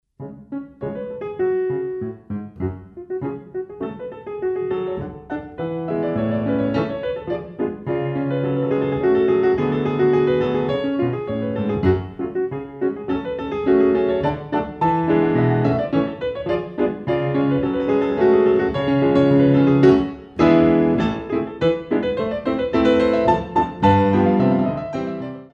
Compositions for Ballet Class
Dégagés en l'air